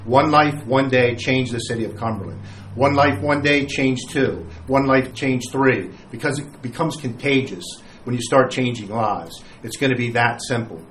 Pyles told the Mayor and City Council he began his career in Cumberland and he will end his career in Cumberland…